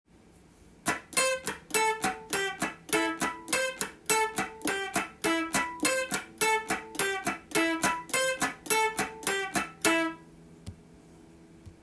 １弦２弦のカッティングしてみました
このように開放弦のノイズ音は入ることがありません。
1.2gen.cutting.m4a